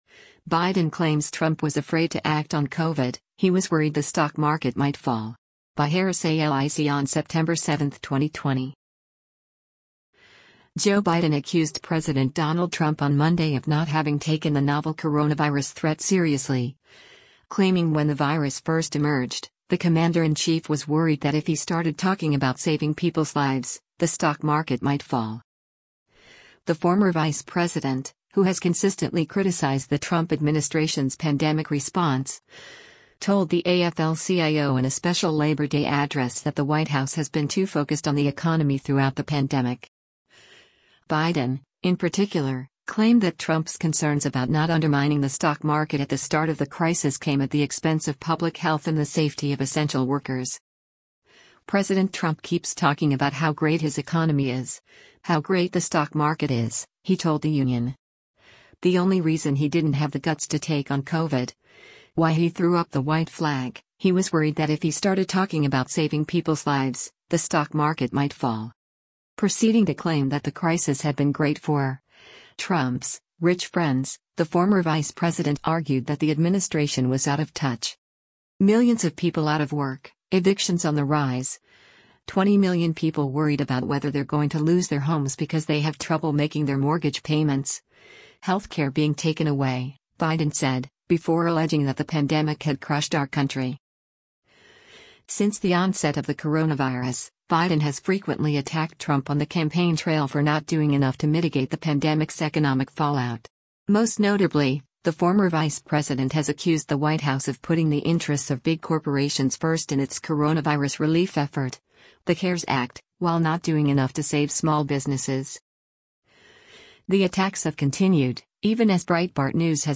The former vice president, who has consistently criticized the Trump administration’s pandemic response, told the AFL-CIO in a special Labor Day address that the White House has been too focused on the economy throughout the pandemic.